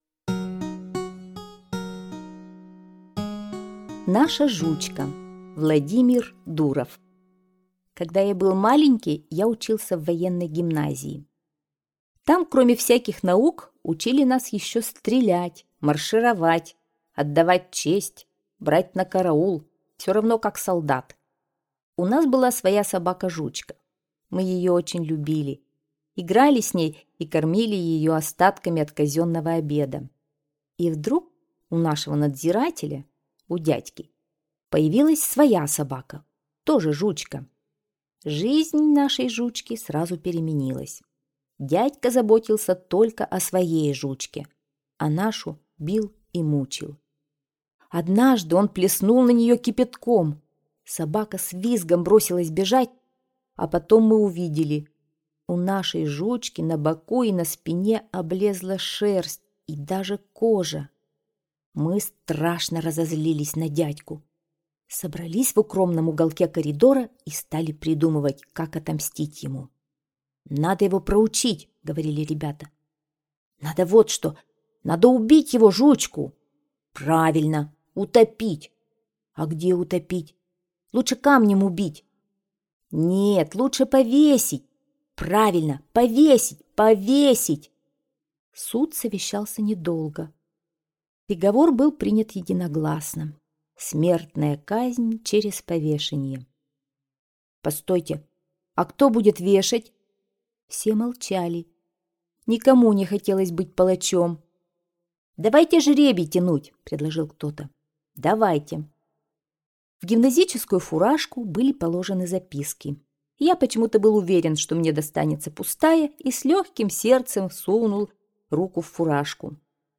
Аудиорассказ «Наша Жучка»